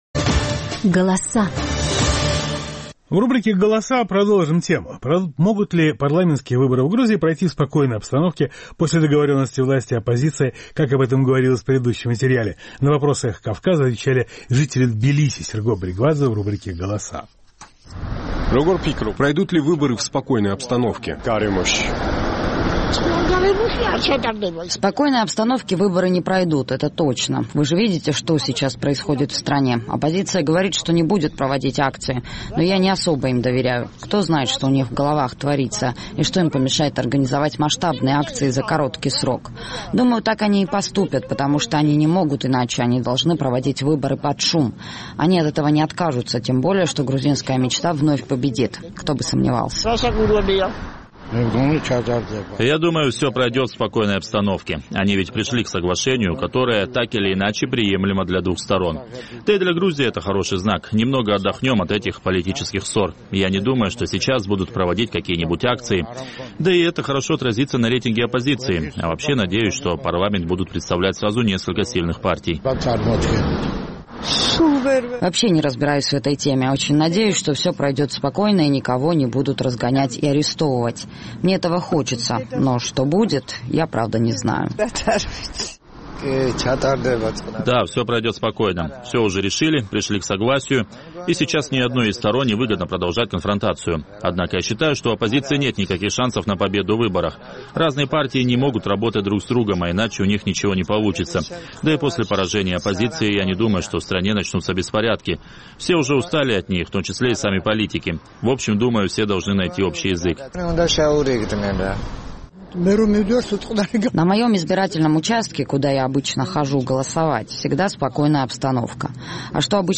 Значит ли это, что предстоящие выборы пройдут в спокойной обстановке? На этот вопрос отвечают жители Тбилиси.